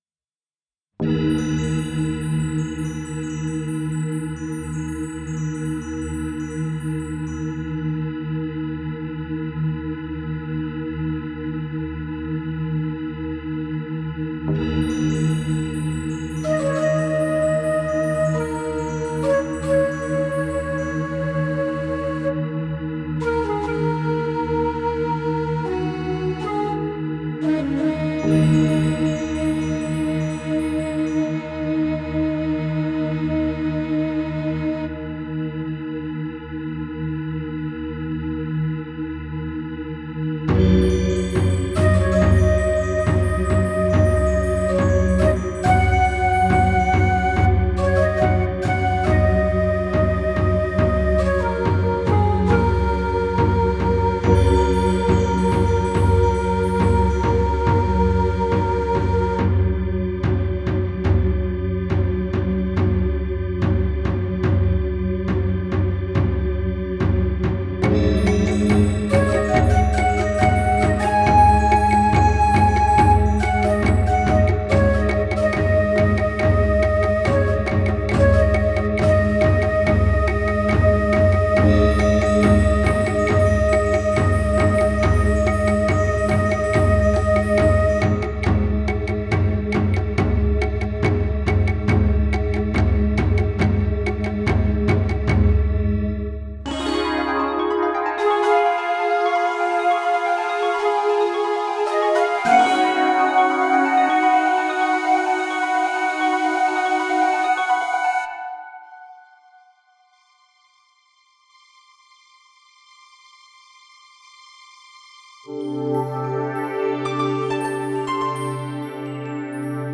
Recorded digitally, using a M-Audio FireWire Audiophile.